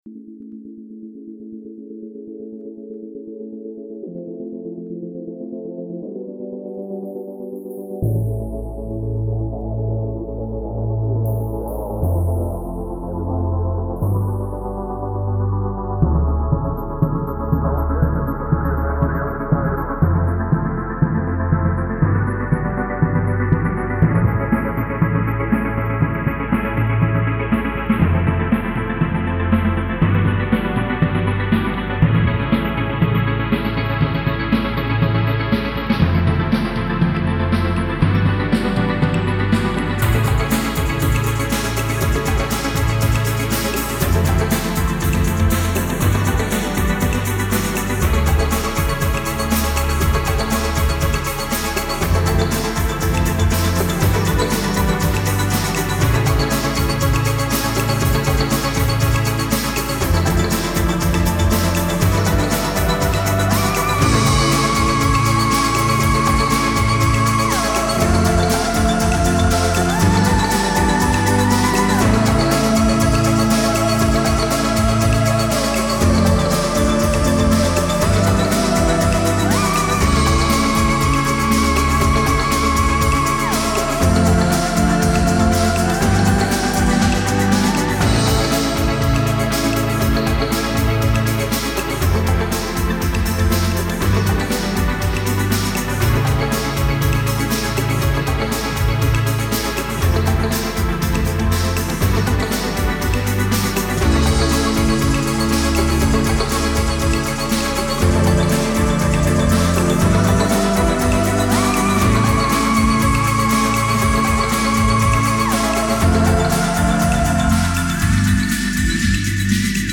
If I said Lo-fi/Experimental what would you say?
There’s depth of sound.